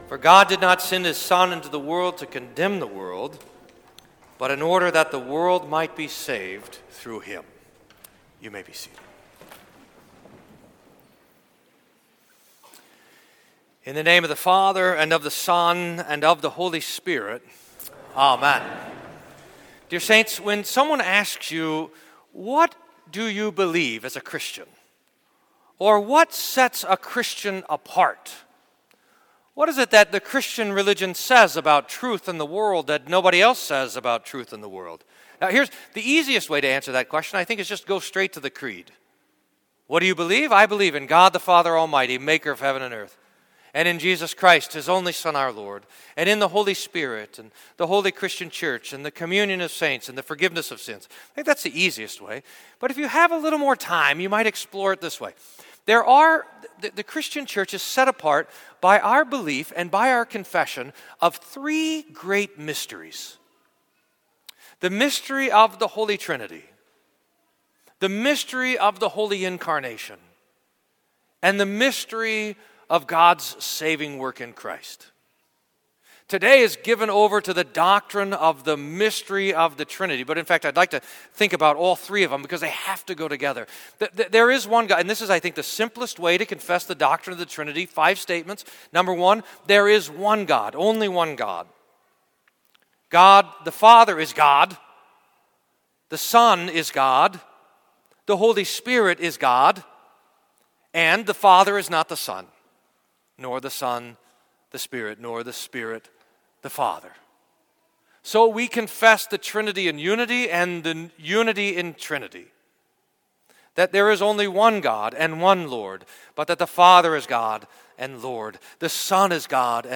Sermon for The Holy Trinity